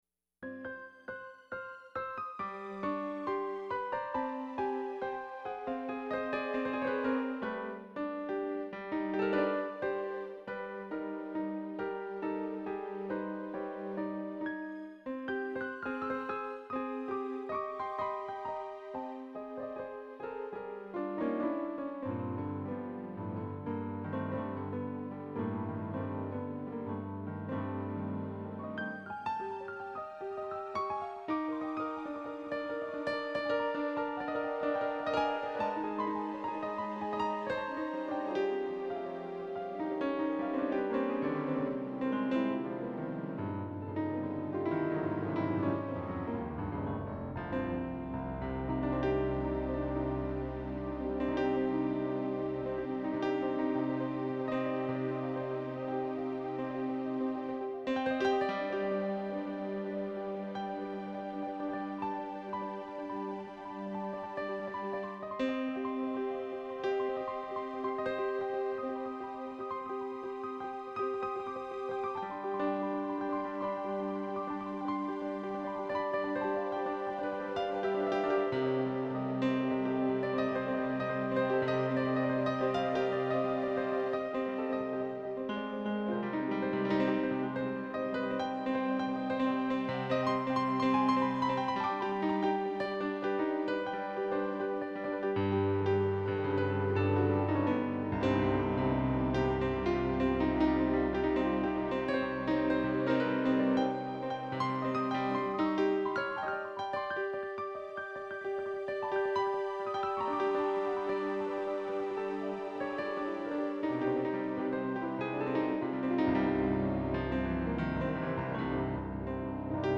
I also play & compose piano music.
These songs are instrumental ... wish they had lyrics but so far none have come to mind.
Many chords aren't melodic and some of the rhythm approaches a seizure—intentional.
It has underlying structure and melody, but is also raindrops in a downpour, lives in the maelstrom of human existence.